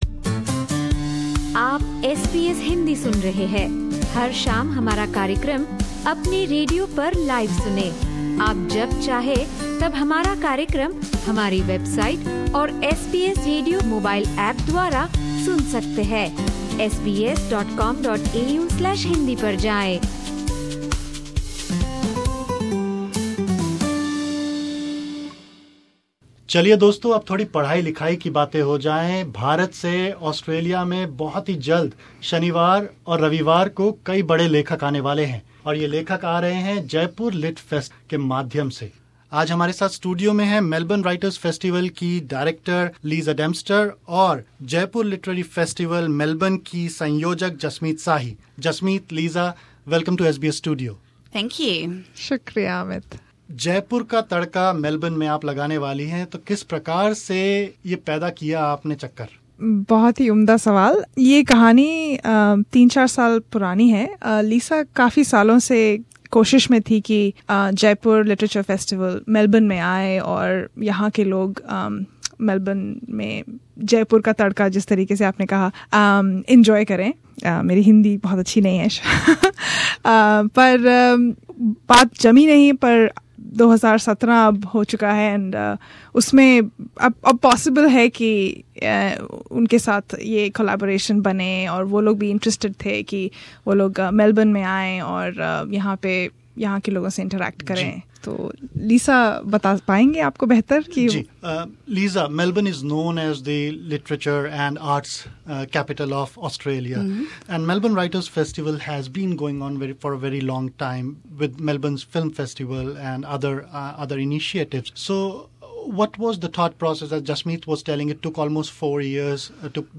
ख़ास बातचीत